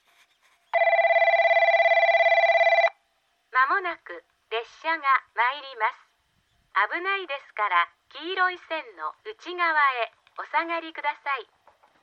この駅では接近放送が設置されています。
接近放送普通　西都城行き接近放送です。